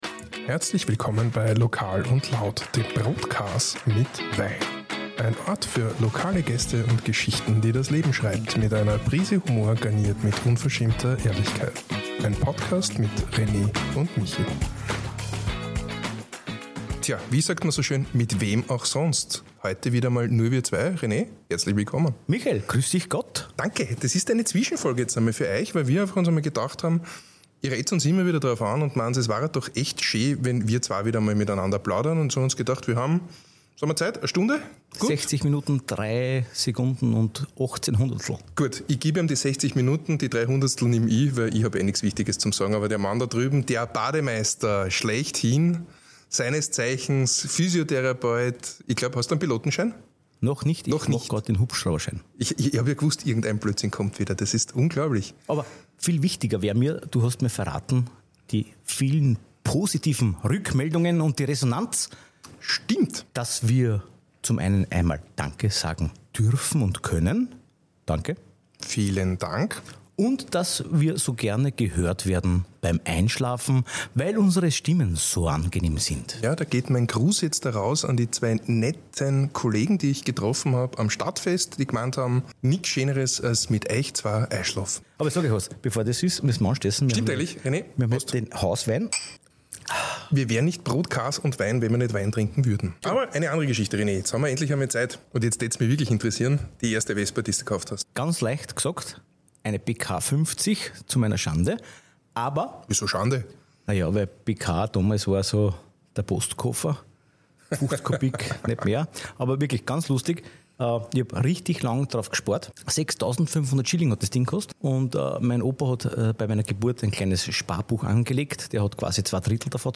Zwischenplauderei ohne Gast Manchmal tut’s gut, einfach drauflos zu quatschen – ganz ohne großen Plan und ohne Gast. In dieser Folge nehmen wir uns die Freiheit, einfach mal über das zu sprechen, was uns gerade beschäftigt: Lokales, Alltägliches und ein bisschen Lautes zwischendurch.